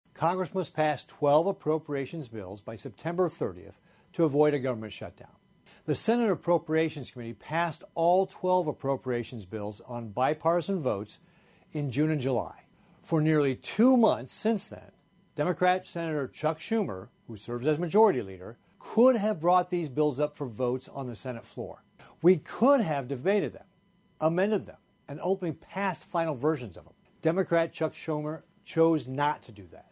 RICKETTS MADE THE COMMENTS ON A CONFERENCE CALL WITH AREA MEDIA.